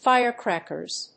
/ˈfaɪɝˌkrækɝz(米国英語), ˈfaɪɜ:ˌkrækɜ:z(英国英語)/